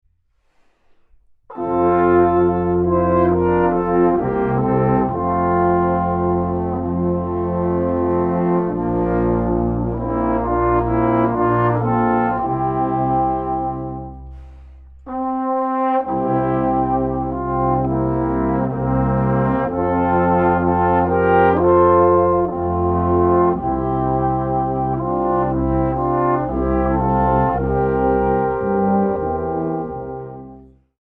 Bläsermusik zur Advents- und Weihnachtszeit